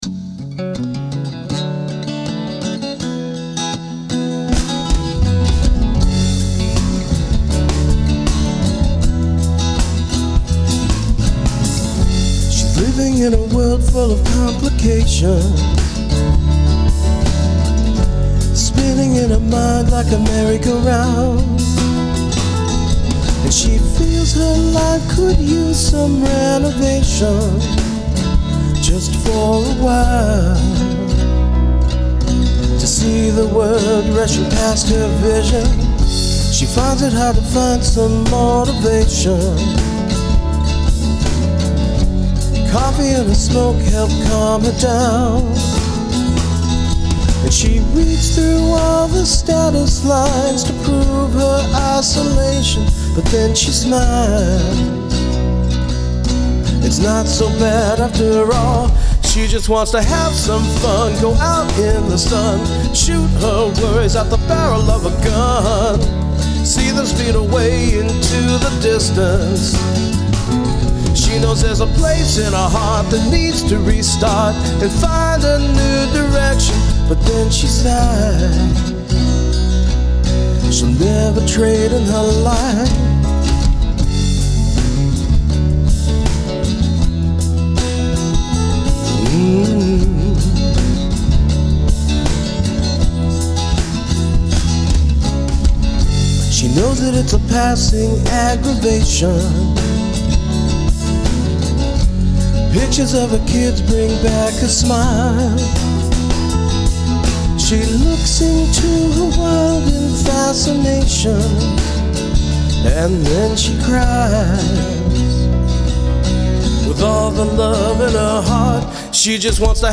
Surprisingly enough, I didn’t use any electric guitars in this. I wanted to kind of leave Alt-Poppish.